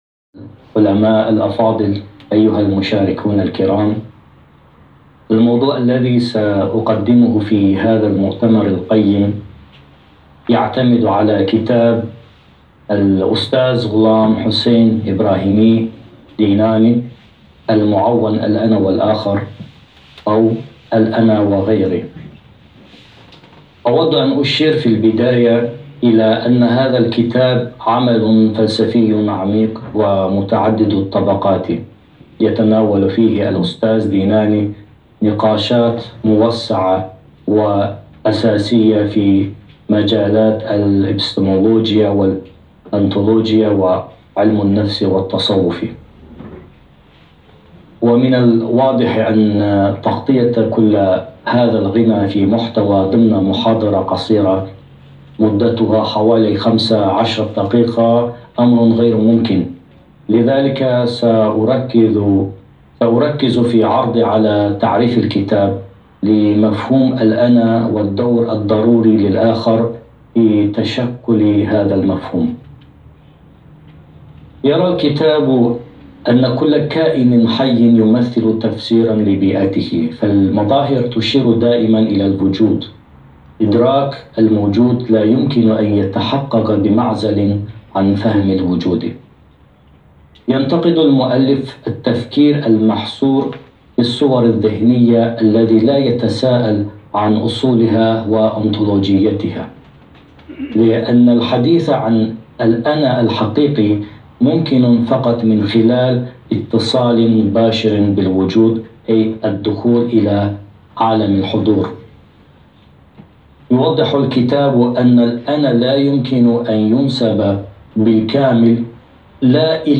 الكلمة التي ألقاها في مؤتمر "قصة الفكر الفلسفي في أفكار الدكتور ديناني" الدولي الذي أقيم مؤخراً في العاصمة الايرانية طهران.